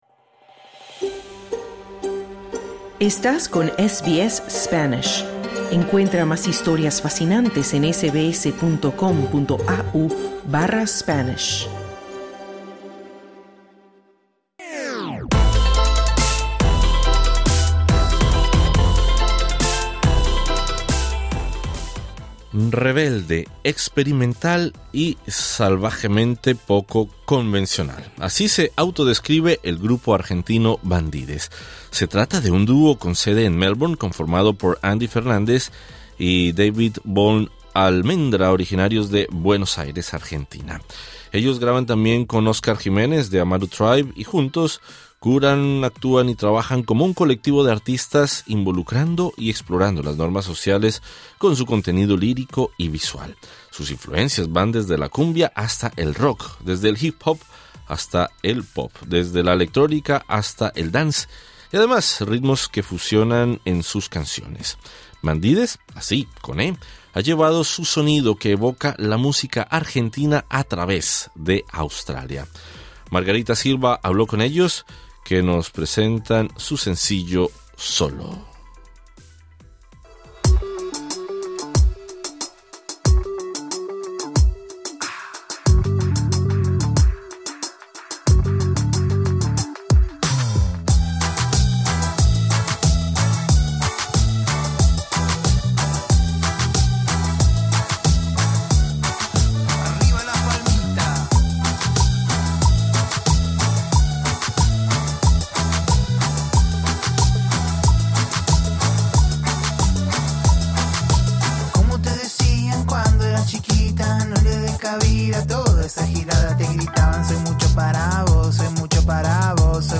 Escucha la entrevista exclusiva de SBS Spanish presionando sobre el icono en la imagen principal.